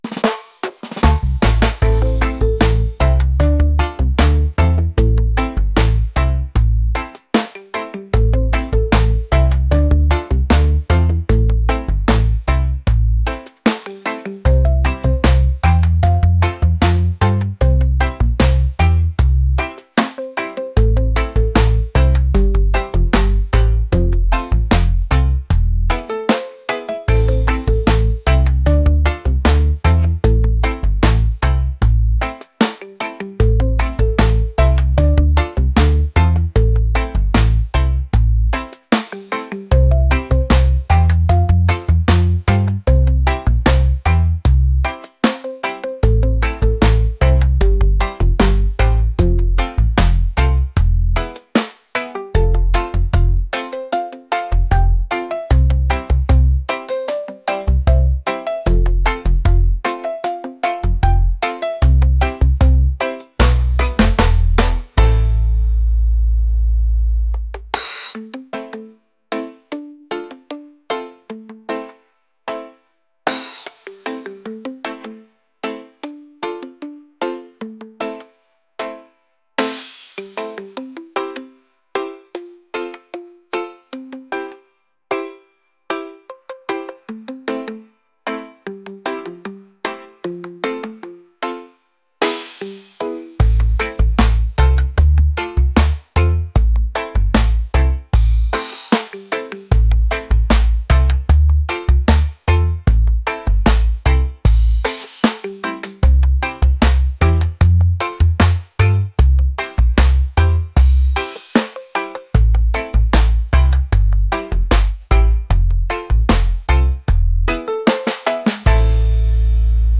laid-back | reggae | groovy